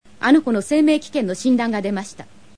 Audio file in Japanese. Sophine, performed by You Inoue